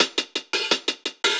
Index of /musicradar/ultimate-hihat-samples/170bpm
UHH_AcoustiHatA_170-02.wav